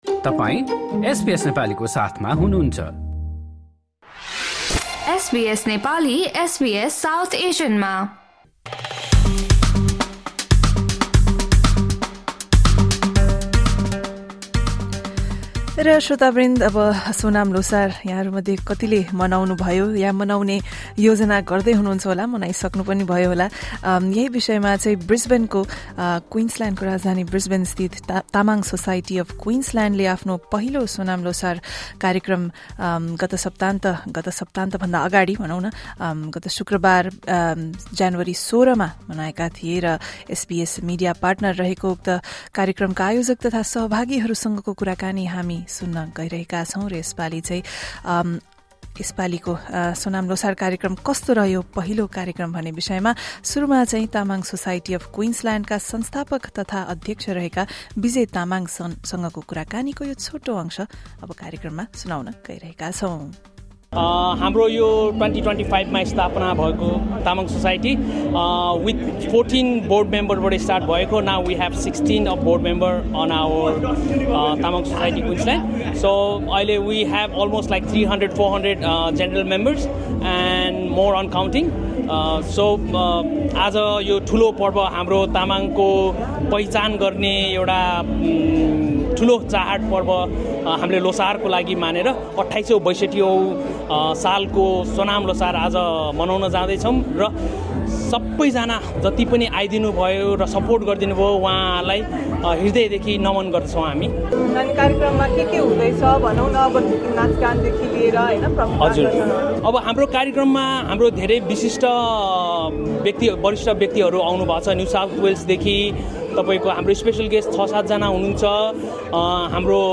क्वीन्स्लयान्डको राजधानी ब्रिसबेनमा तामाङ सोसाइटी अफ क्वीन्सल्यान्डले शुक्रवार, ज्यानुअरी १६ मा सोनाम ल्होसार कार्यक्रम आयोजना गर्दै घोडा वर्षलाई स्वागत गरेको छ। उक्त कार्यक्रमका आयोजक तथा सहभागीहरूसँग ल्होसारको महत्त्व, सांस्कृतिक पहिचान र दोस्रो पुस्तालाई ग्यान हस्तान्तरण गर्ने विषयमा एसबीएस नेपालीले गरेको कुराकानी सुन्नुहोस्।